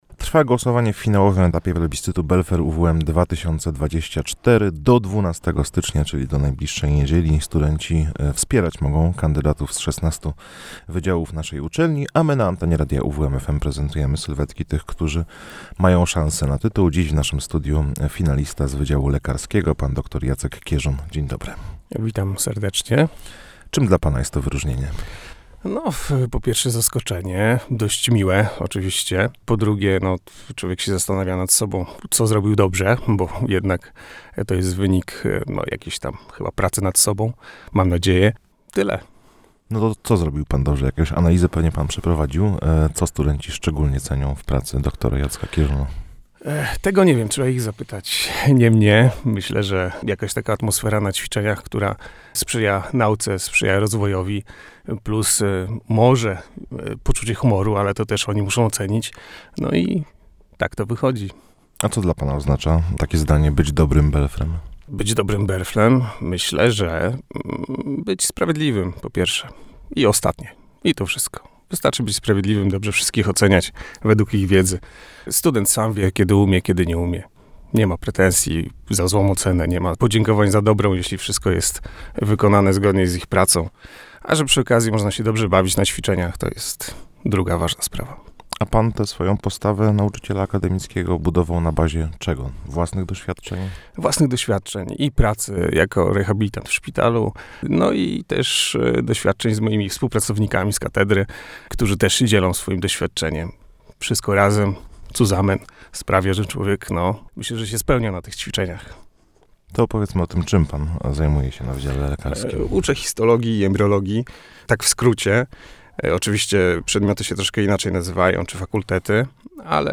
– mówił w studiu Radia UWM FM.